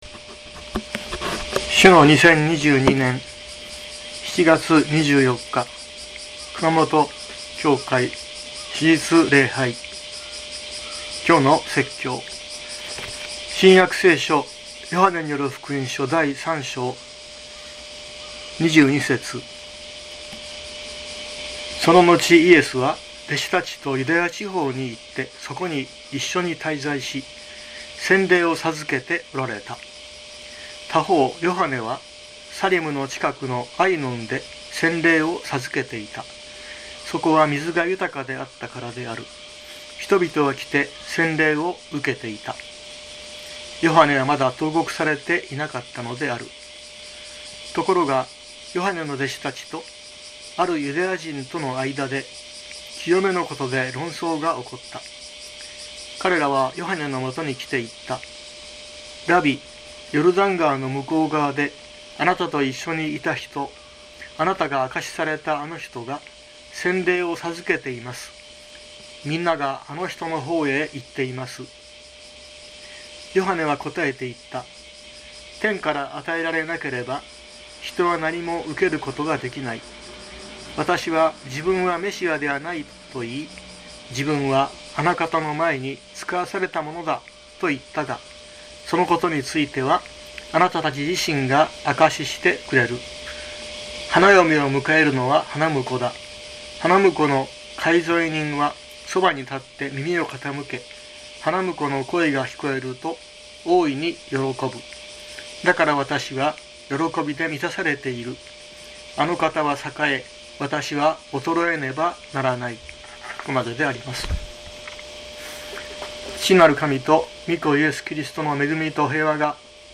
熊本教会。説教アーカイブ。